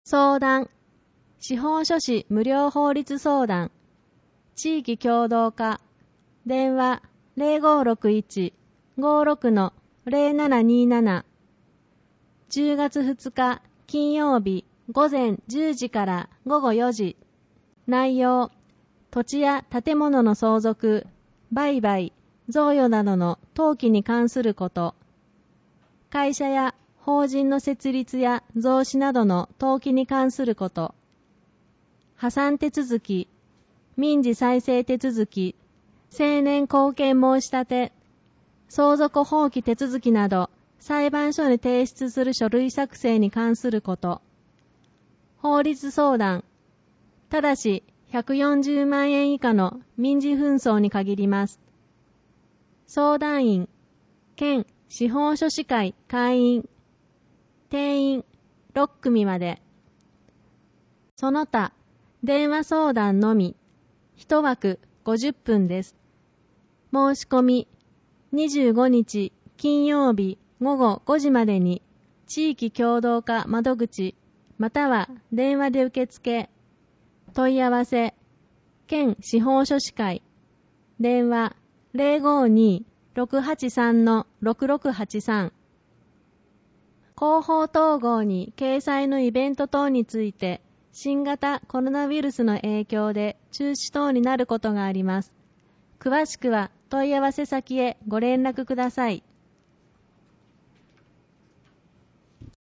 広報とうごう音訳版（2020年9月号）